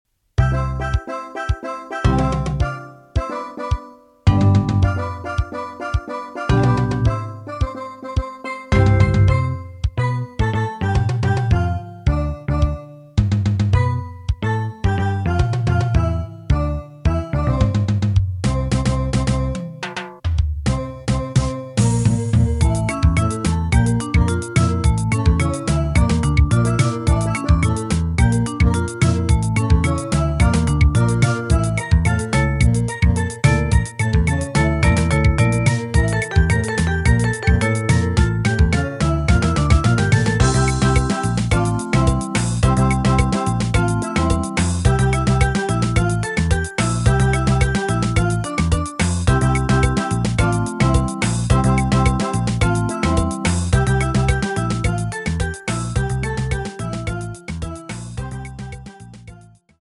Genre: Steel Drum Band
Alternating between Calypso and Flamenco feels
Lead Pan
Cello
Vibraphone
Electric Bass
Drum set
Percussion (congas, timbales, jam block)